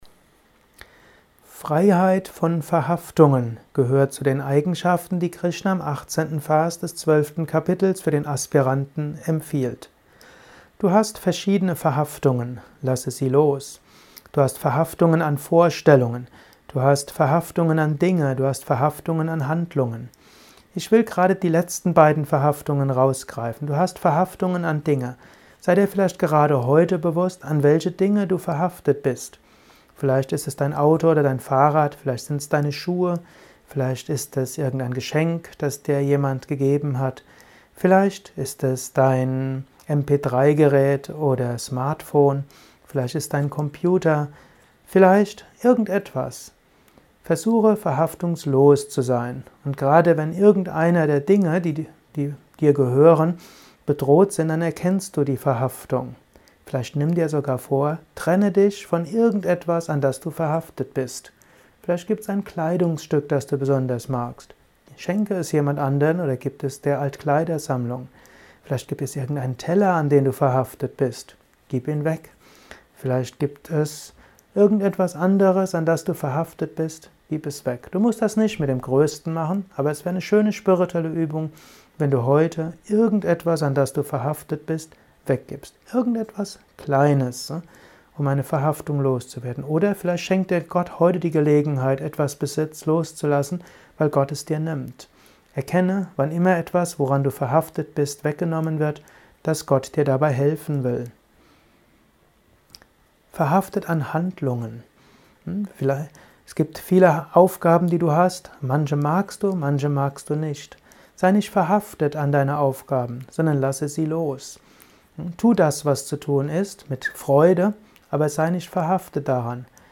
kurzer Kommentar als Inspiration für den heutigen Tag von und